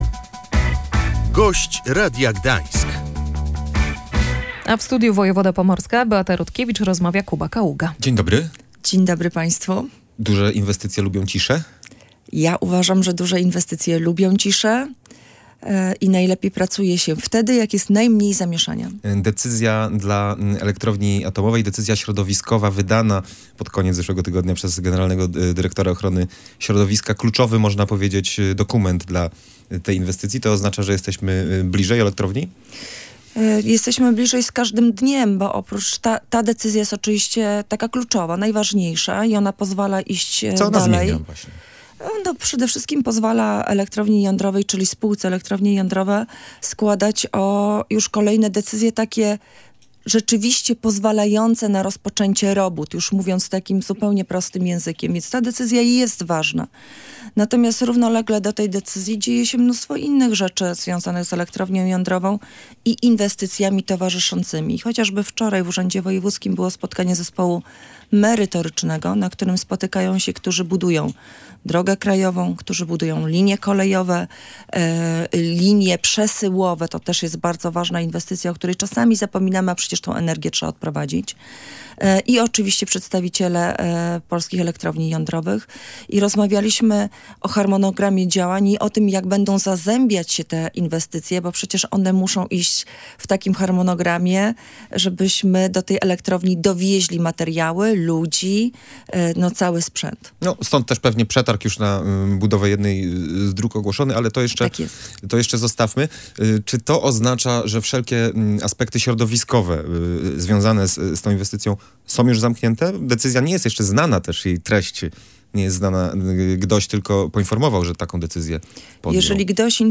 Wojewoda pomorska Beata Rutkiewicz mówiła, że oznacza to wolną rękę dla dalszych działań.